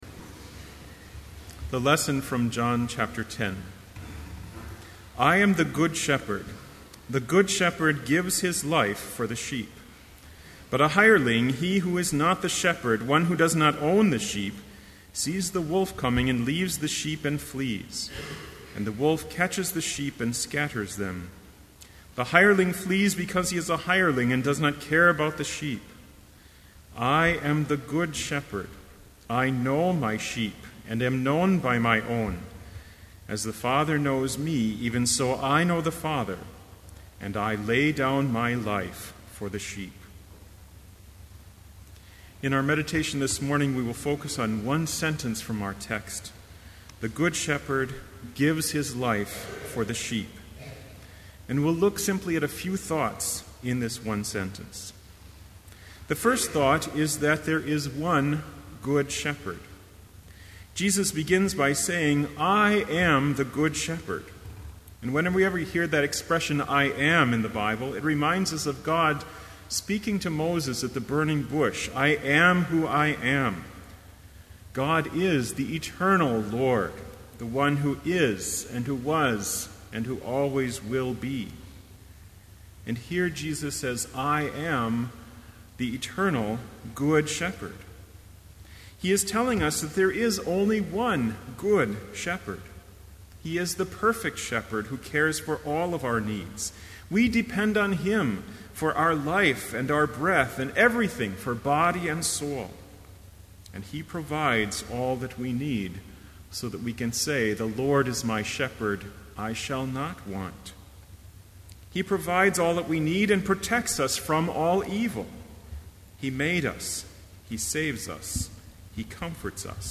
Sermon Only
This Chapel Service was held in Trinity Chapel at Bethany Lutheran College on Wednesday, April 25, 2012, at 10 a.m. Page and hymn numbers are from the Evangelical Lutheran Hymnary.